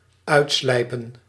Ääntäminen
IPA: /ˈœy̯tˌslɛi̯pə(n)/